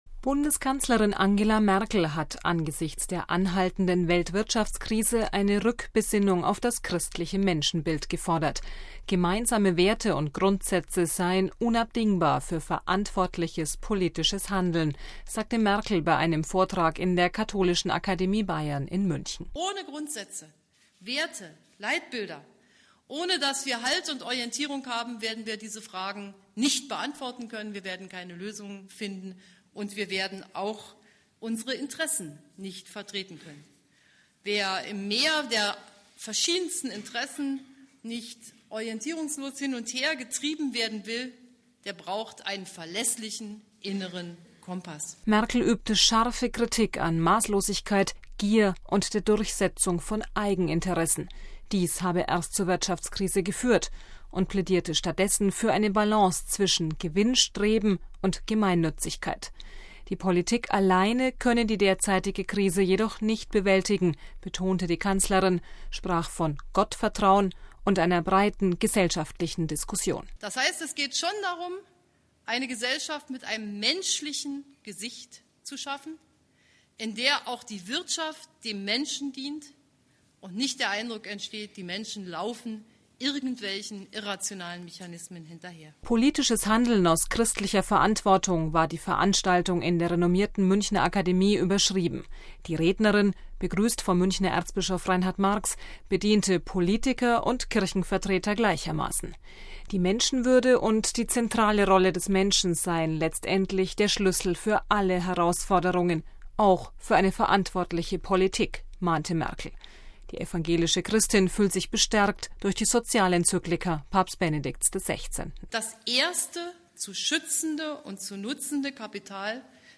MP3 Bundeskanzlerin Angela Merkel (CDU) hat angesichts der anhaltenden Weltwirtschaftskrise eine Rückbesinnung auf das christliche Menschenbild gefordert. Gemeinsame Werte und Grundsätze seien unabdingbar für verantwortliches politisches Handeln, sagte Merkel bei einem Vortrag in der Katholischen Akademie Bayern in München.